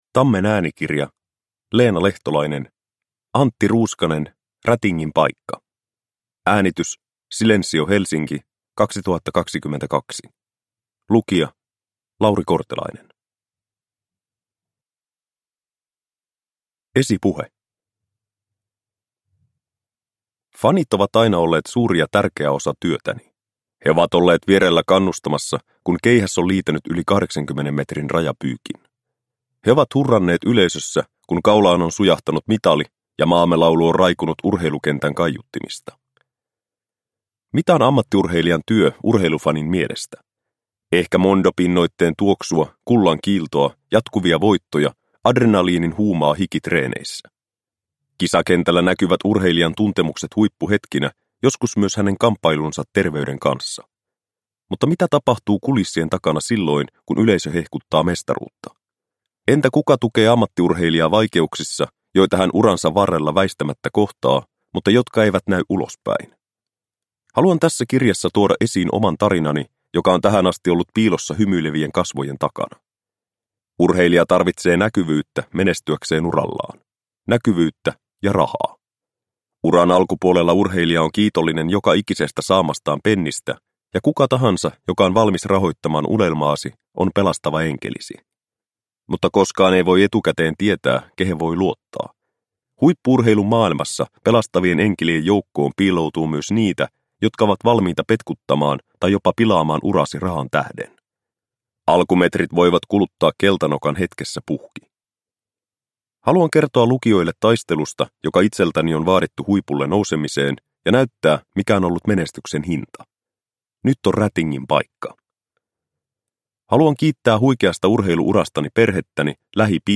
Antti Ruuskanen - Rätingin paikka – Ljudbok – Laddas ner